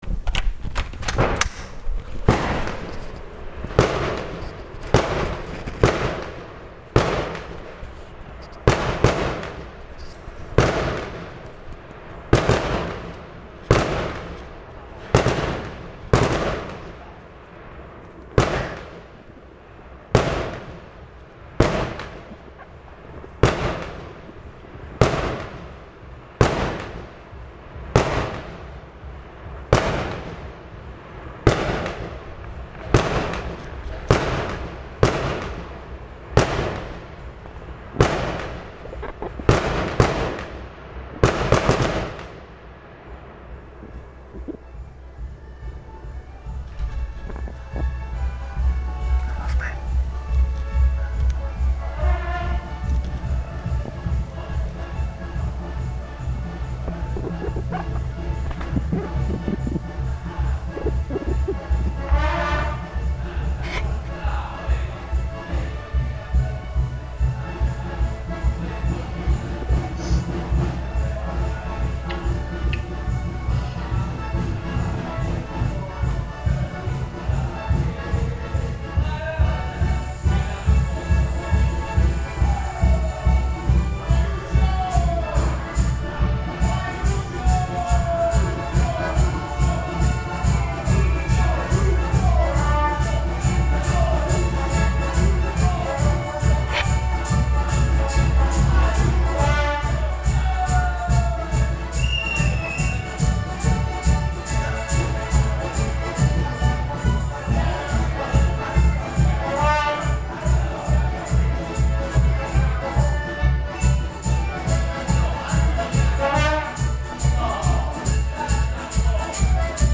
Piazza Municipio Con 10 Persone, del 1 gen 2022, ore 00.15
3-Fuochi-DArtificio-E-3a-Squadra-del-1-gen.mp3